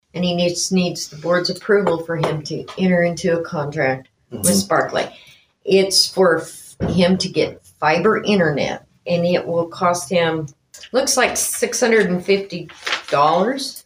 Nowata County Clerk Kay Spurgeon on the details.